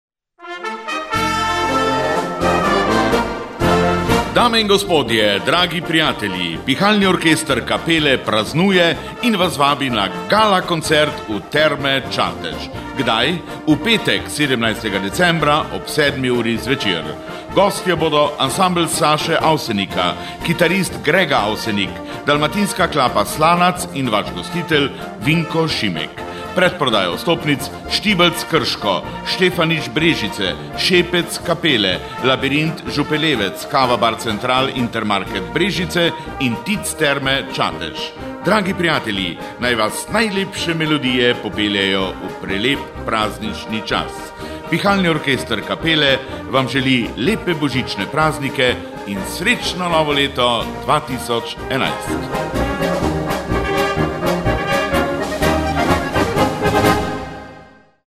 Pihalni orkester Kapele in Vinko Šimek vabita...)